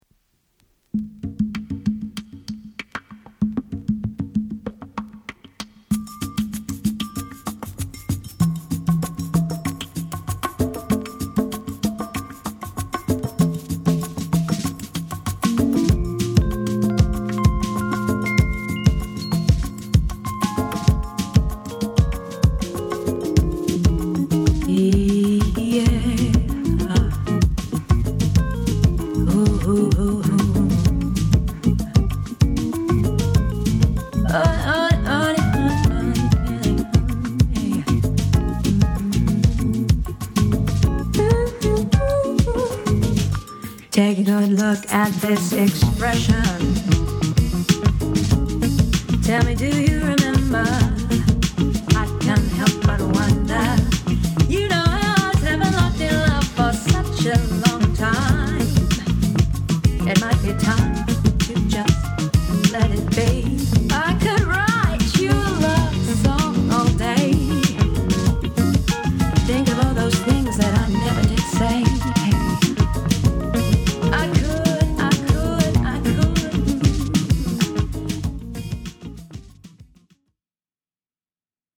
大所帯ディープ・ファンクバンド AFRO FUNK リミックス・シングル 33RPM.